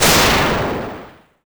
Thunder.wav